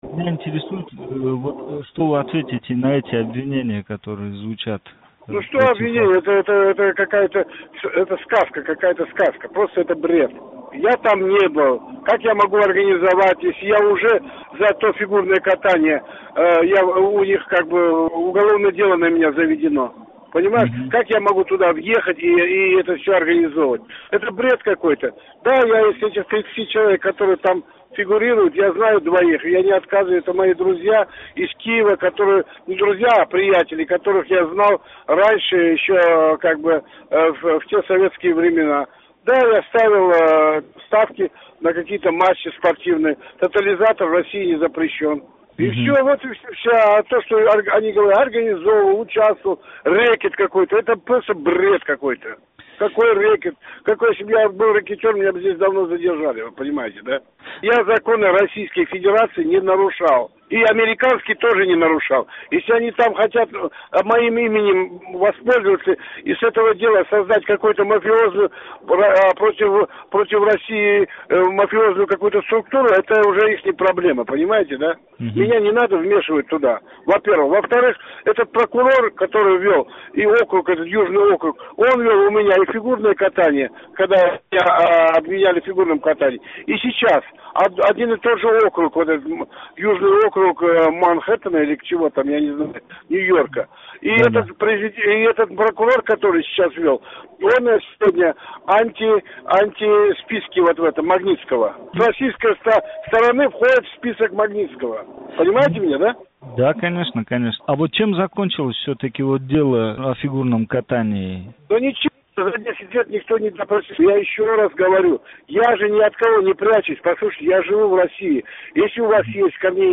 Олимжон Тўхтаохунов билан суҳбат